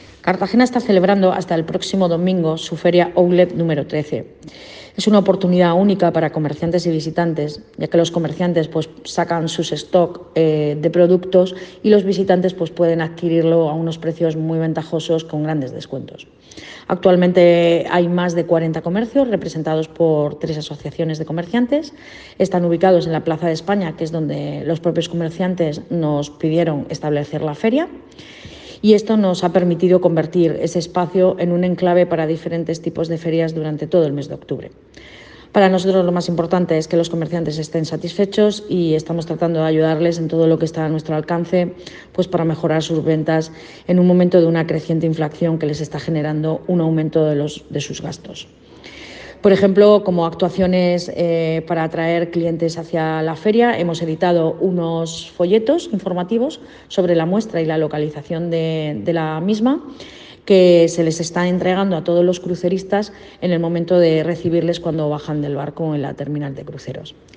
Enlace a Declaraciones de Belén Romero